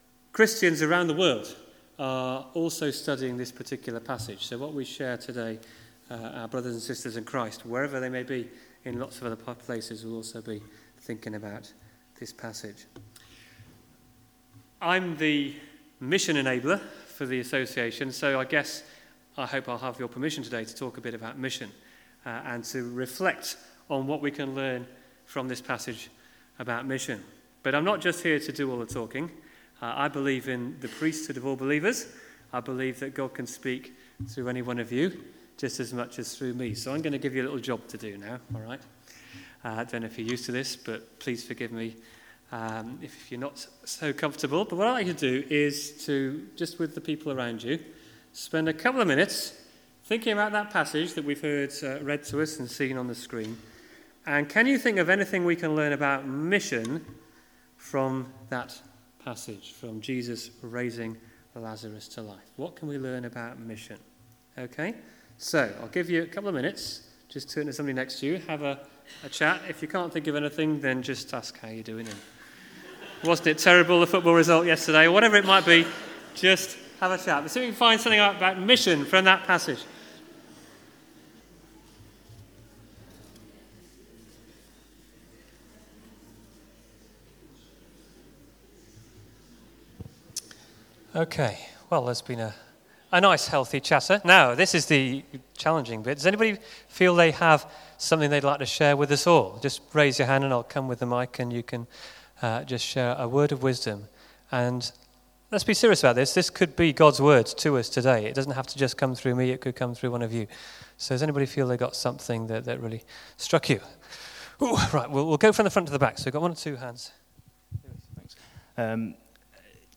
Morning Service , Guest Speaker